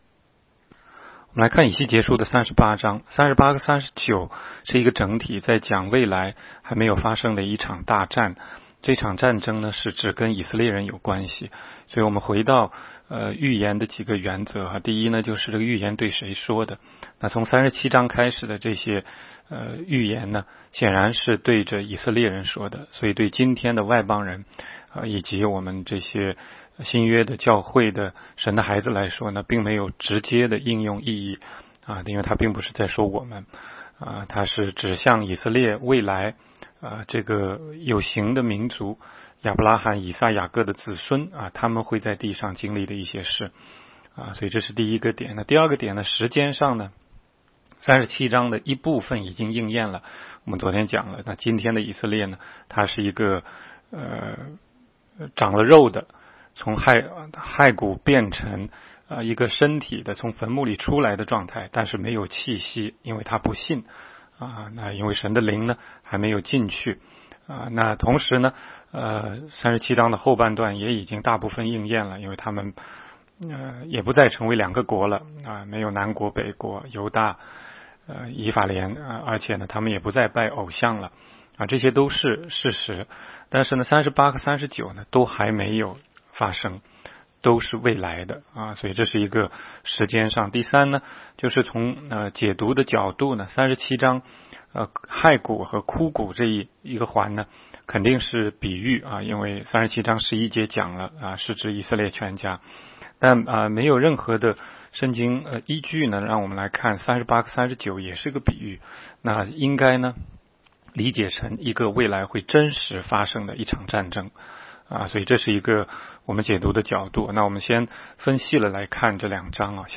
16街讲道录音 - 每日读经 -《以西结书》38章